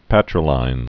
(pătrə-līn)